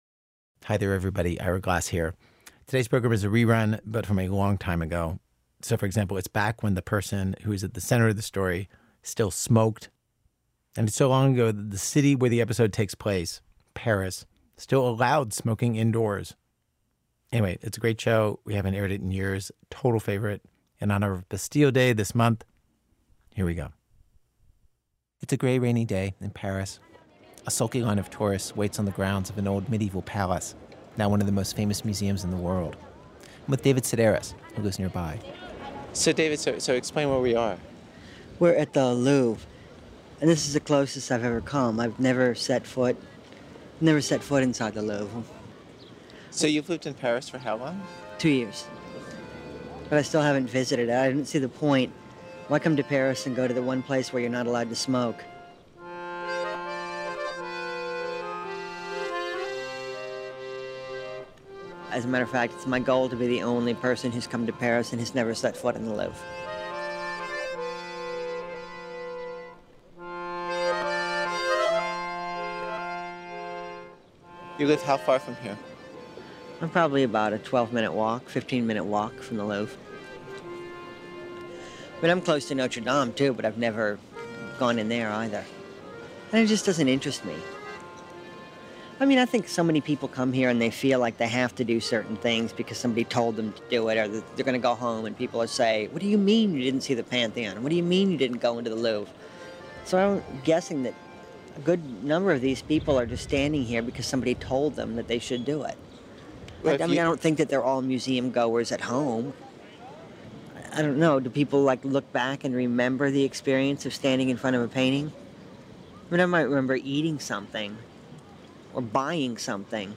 David Sedaris takes Ira on a tour of his favorite spots in Paris.
Note: The internet version of this episode contains un-beeped curse words.